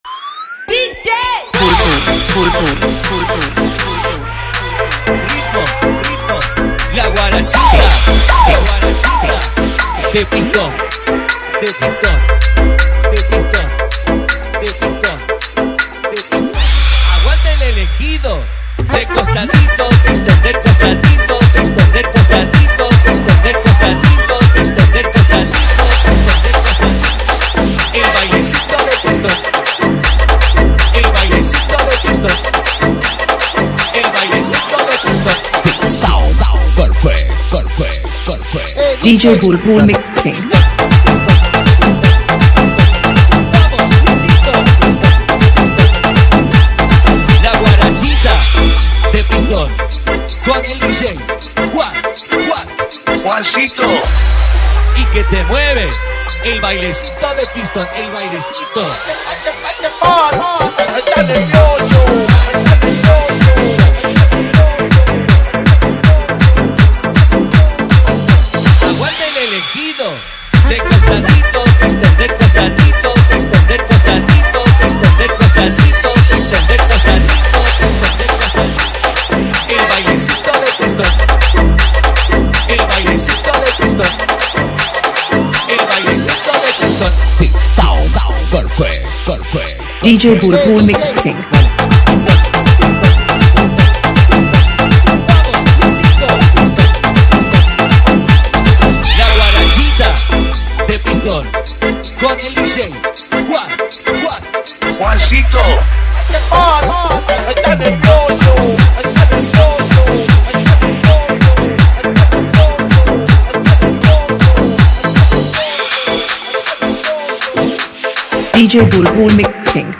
Category : Mashup Remix Song